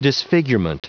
Prononciation du mot : disfigurement